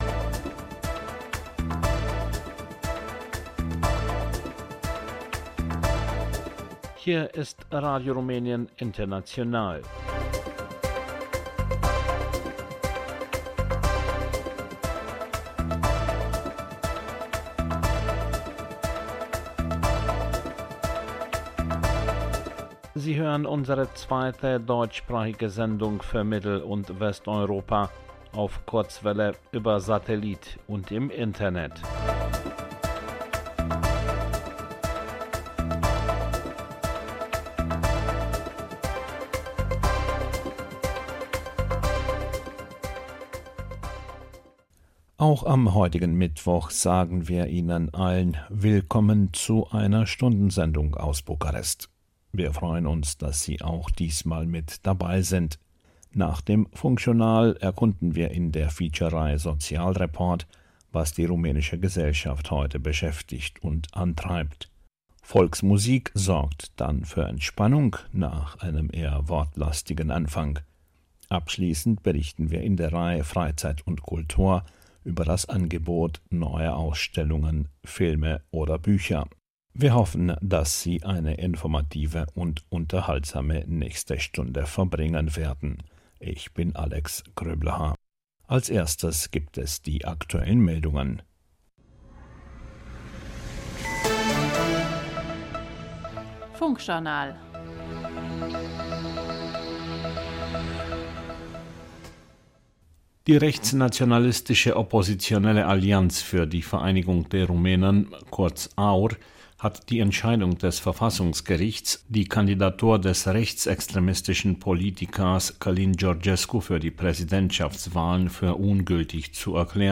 Funkjournal, Sozialreport, Volksmusik, Blickpunkt Kultur